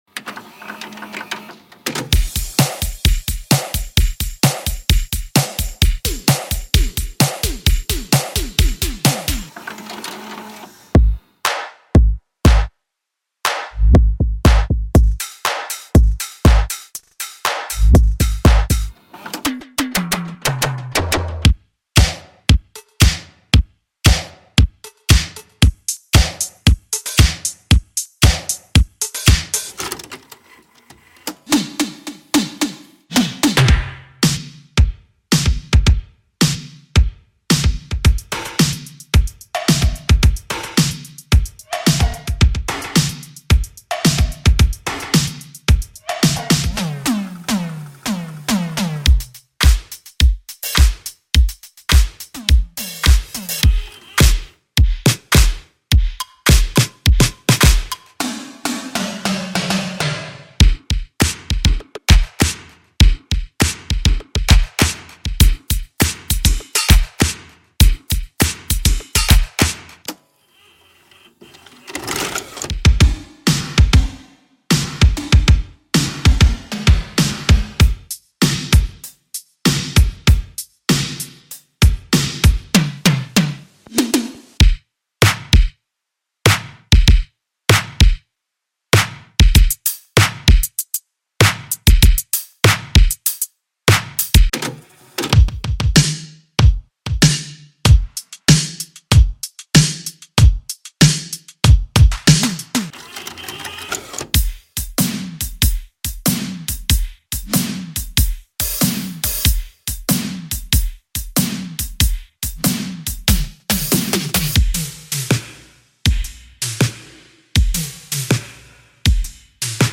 Synthwave / Retrowave
This bundle contains four retro drum sample packs with 850 wav samples in total. Everything in 80s style, top quality, designed for easy use.
115 Drum Loops
30 Cassette Tape Foley